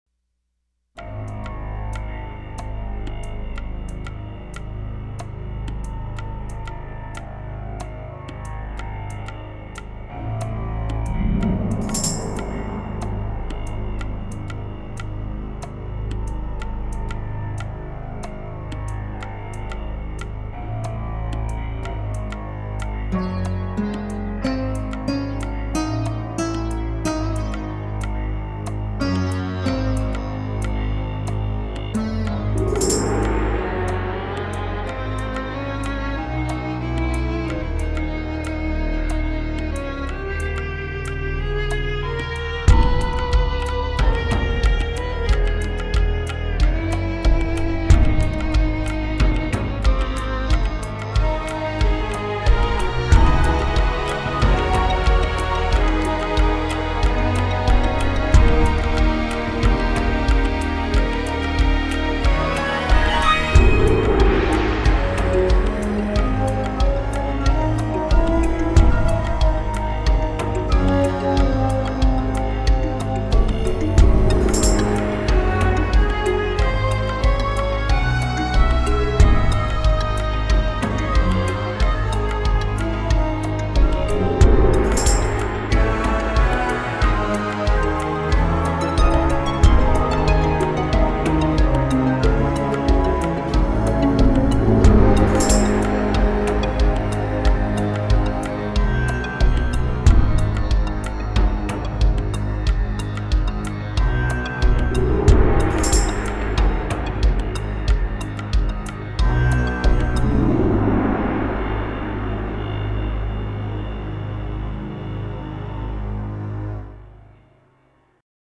В наше время создается огромное количество псевдо-египетской музыки.